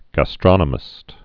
(gă-strŏnə-mĭst)